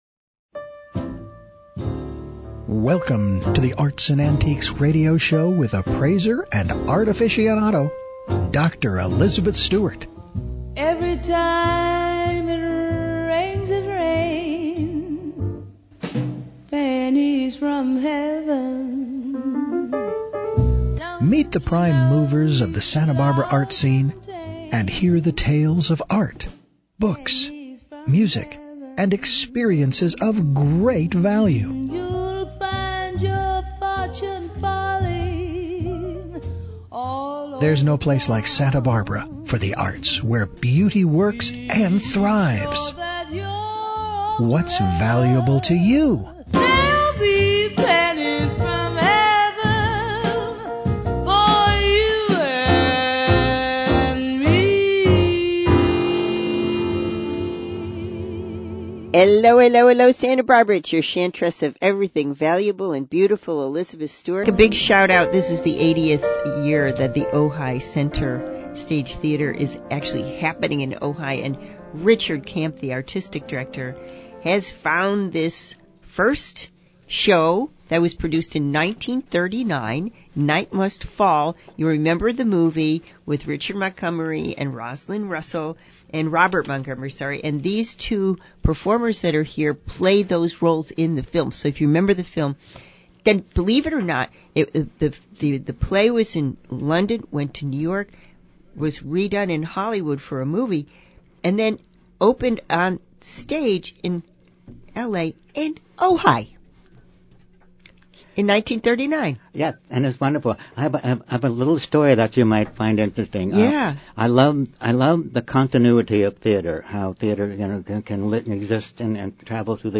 NIGHT MUST FALL RADIO INTERVIEW